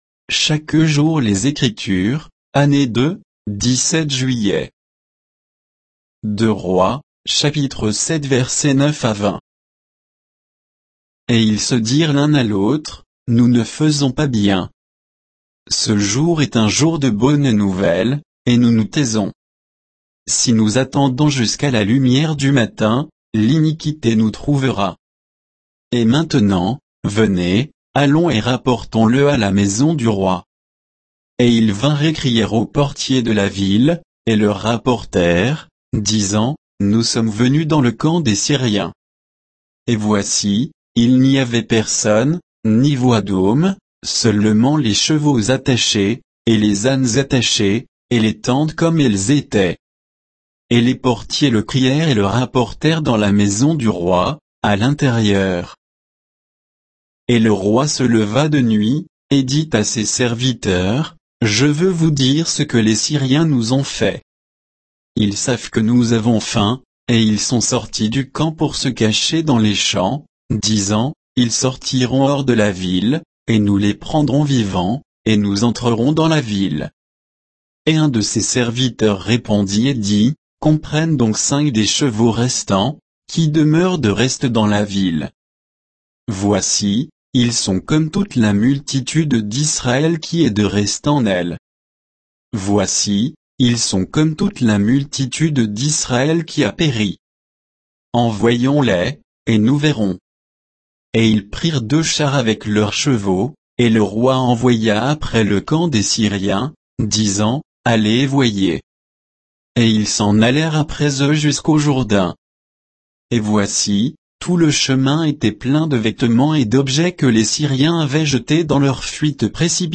Méditation quoditienne de Chaque jour les Écritures sur 2 Rois 7